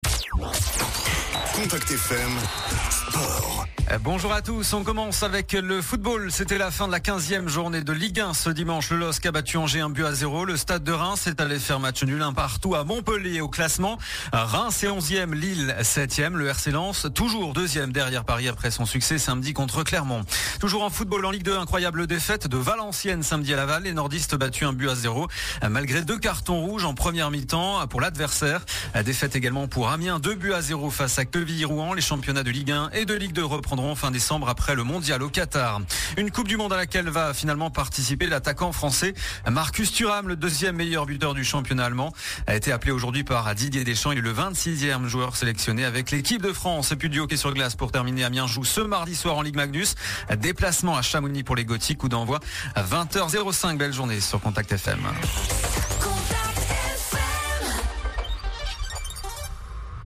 Le journal des sports du lundi 14 novembre